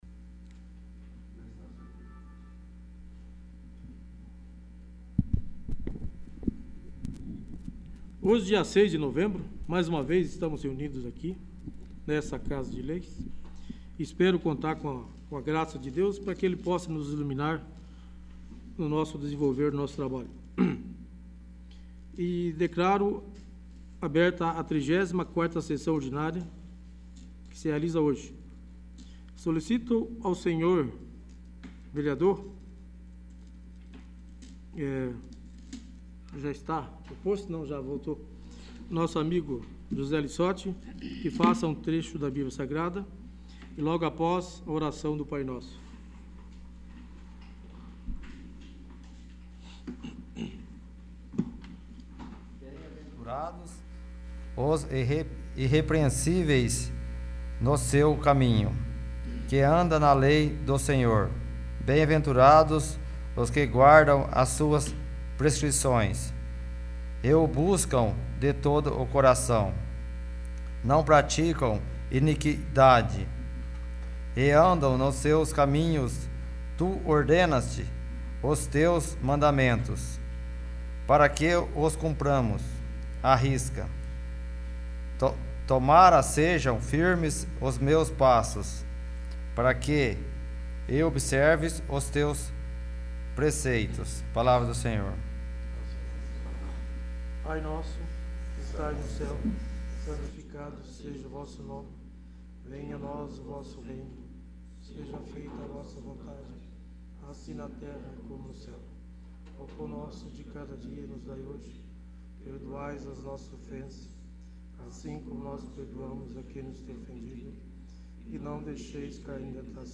34º. Sessão Ordinária